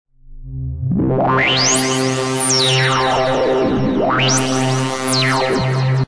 ♪とりあえず右クリックでスライダーを動かしてみた音♪(mp3)
劇的に変化してますね。ただ、何となく変化がガタガタしてぎこちない気がしませんか？